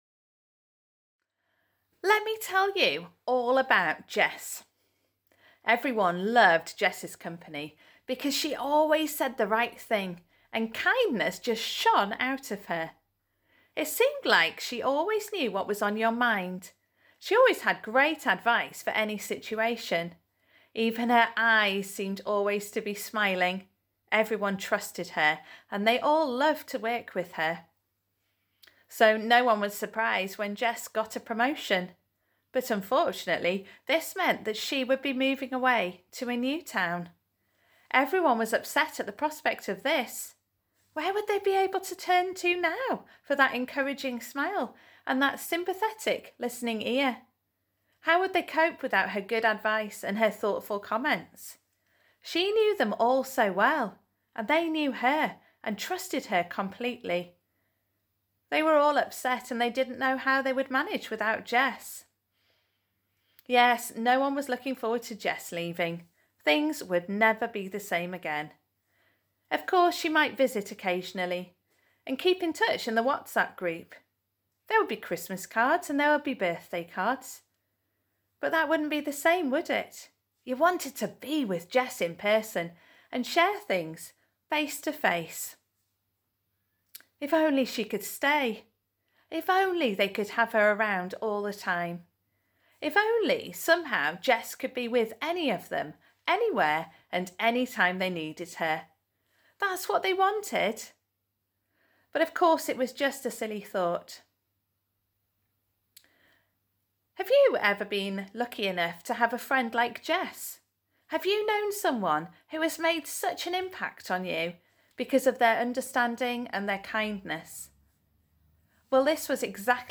Pre-recorded video and audio.
Morning Service